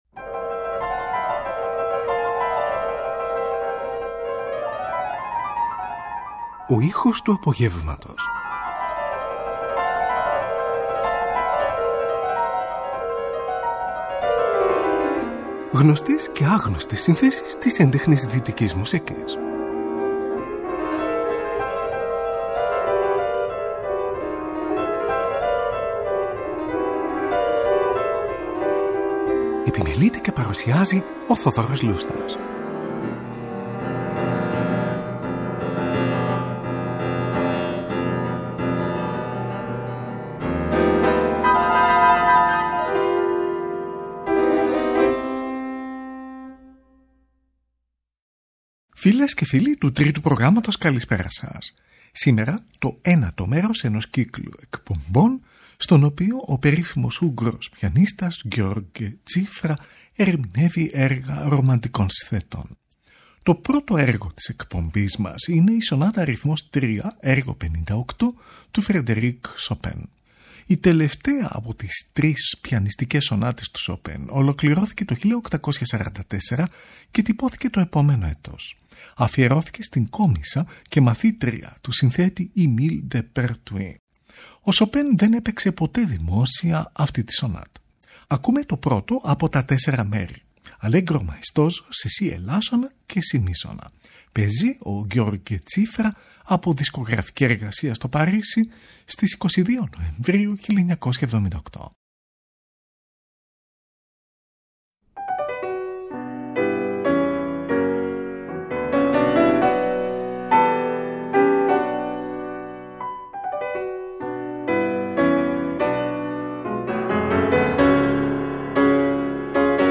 O Ούγγρος Πιανίστας György Cziffra Ερμηνεύει Έργα Ρομαντικών Συνθετών – 9o Μέρος | Τρίτη 21 Ιανουαρίου 2025
Πιανιστικη Μεταγραφη